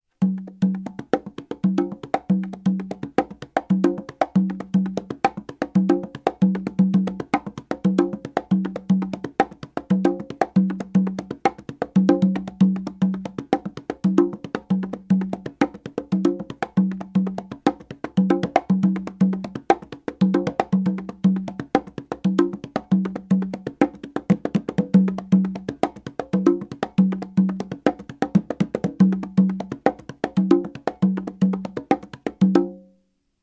Latin Groove 2
RHYTHM INFO Right hand is ALWAYS on the tumba.
LatinGroove2.mp3